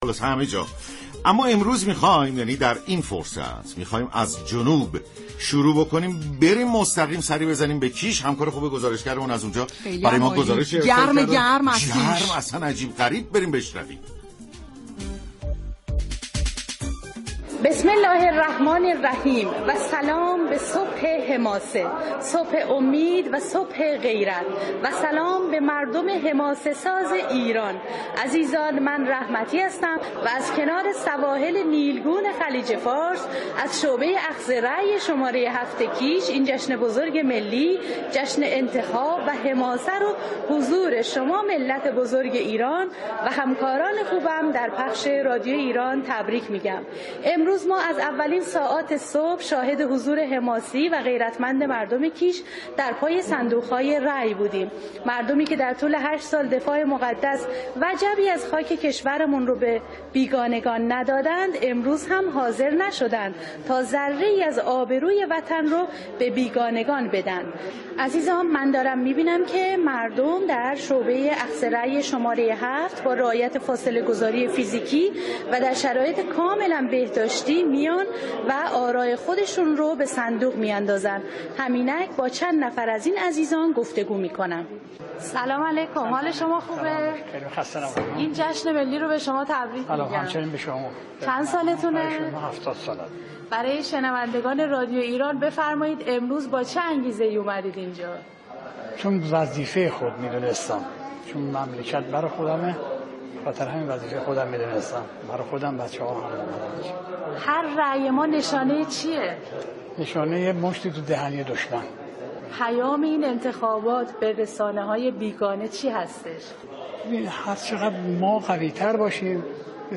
گزارش از مركز كیش.